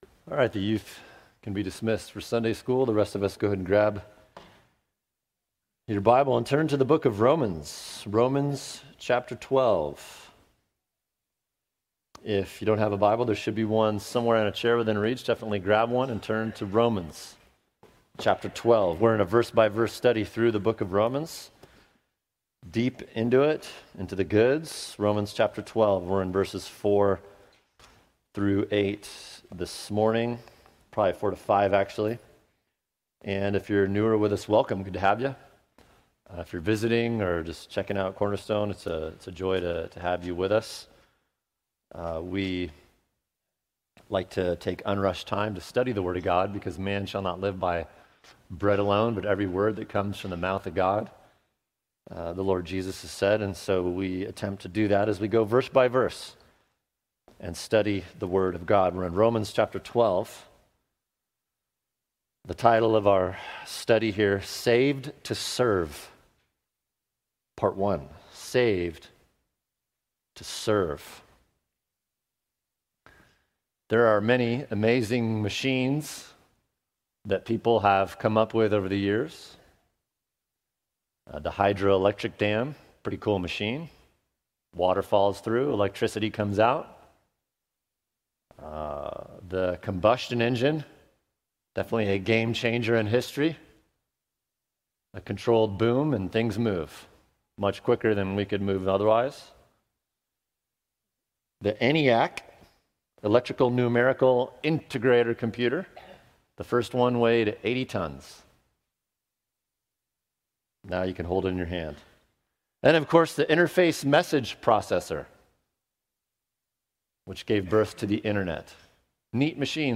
[sermon] Saved to Serve (Part 1) Romans 12:4-8 | Cornerstone Church - Jackson Hole